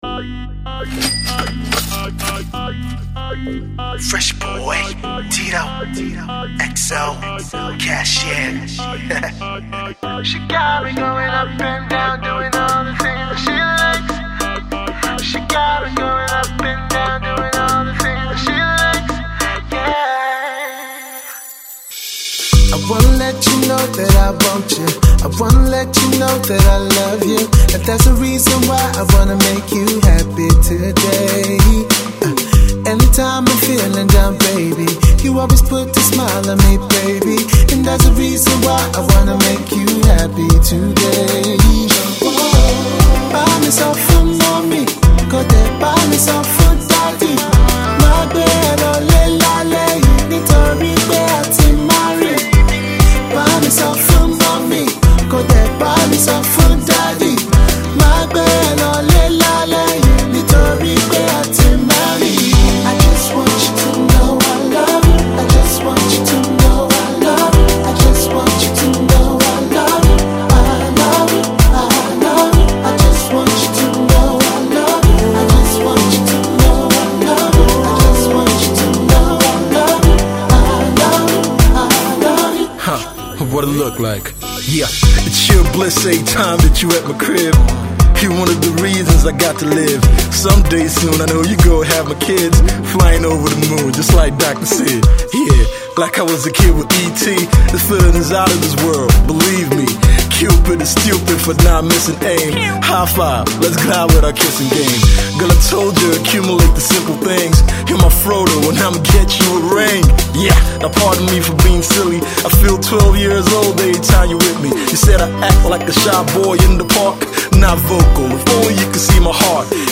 returns with a wedding jam
The lovely love jam
silky vocals